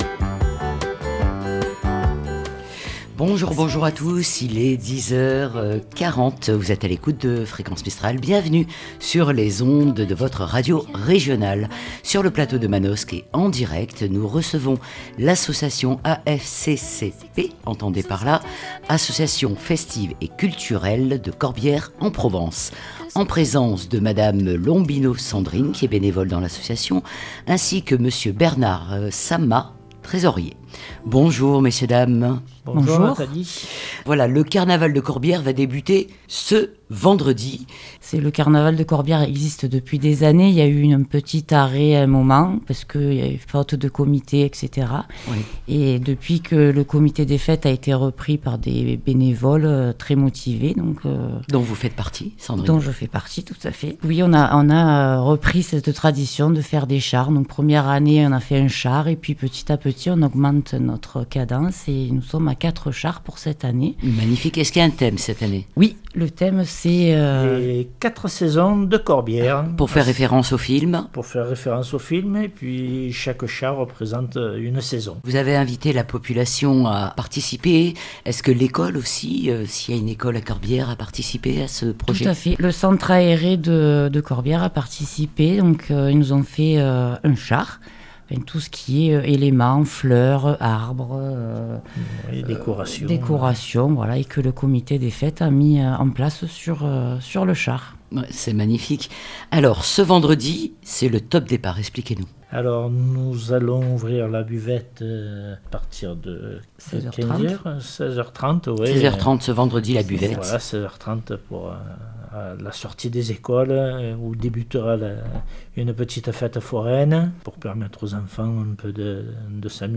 Carnaval de Corbières.mp3 (7.72 Mo) En direct sur le plateau de Fréquence Mistral Manosque, nous recevons l'association AFCCP ( association festive et culturelle Corbières en Provence) pour le lancement du carnaval de Corbières.